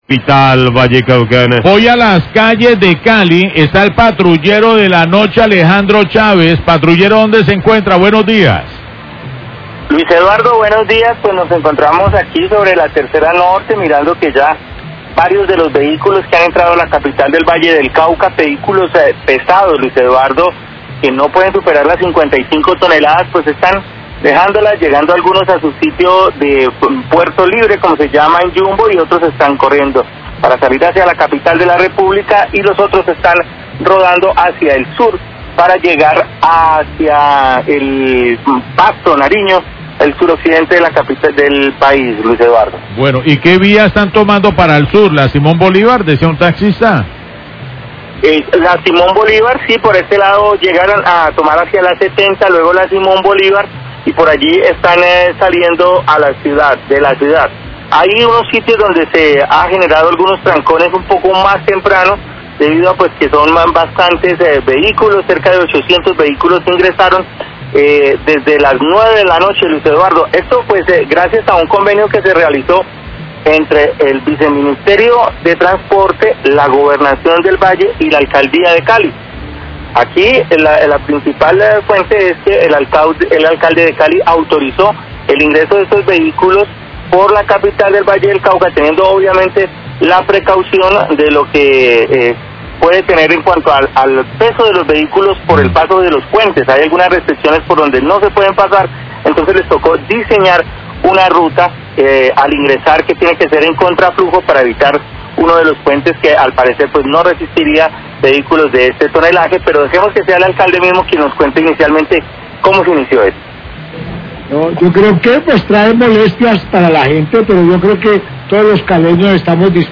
Radio
Patrullero de la noche informa sobre la movilidad de los vehículos de carga que entran a Cali por Yumbo y atraviesan la ciudad.